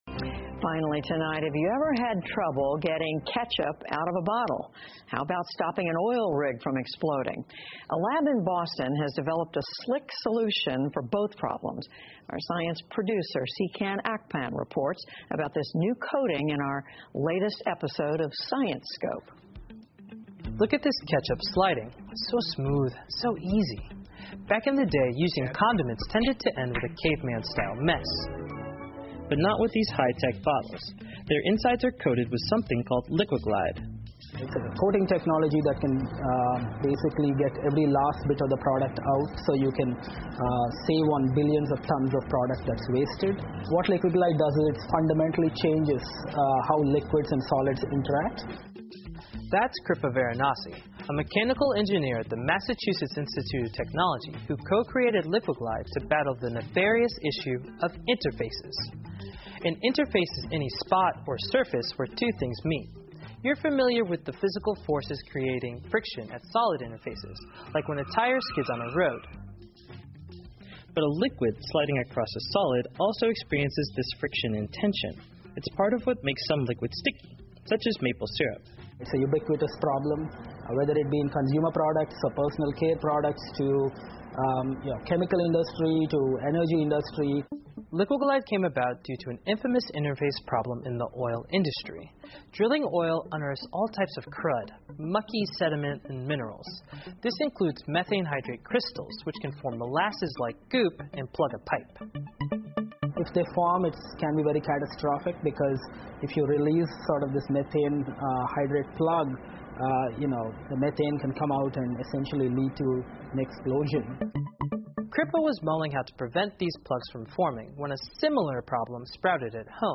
PBS高端访谈:能让番茄酱、牙膏和胶水从容器中滑出的高科技涂料 听力文件下载—在线英语听力室